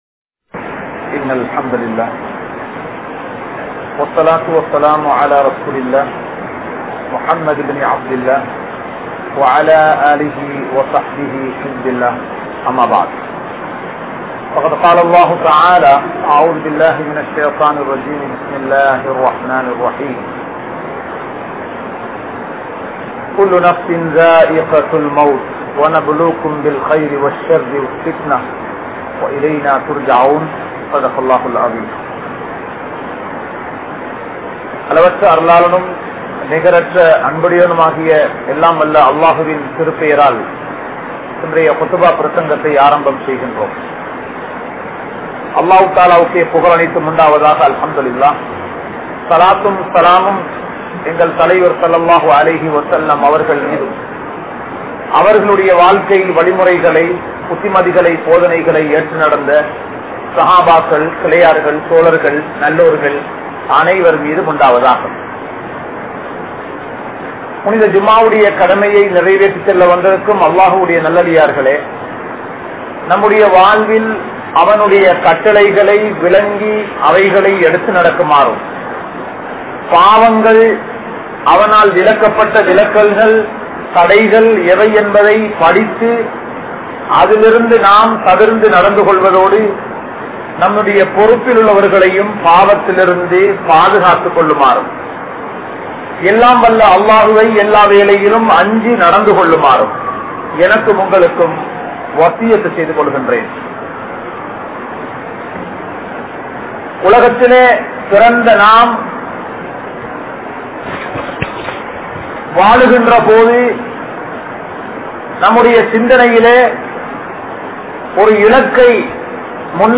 Uir Pirium Nimidam (உயிர் பிரியும் நிமிடம்) | Audio Bayans | All Ceylon Muslim Youth Community | Addalaichenai
Junction Jumua Masjith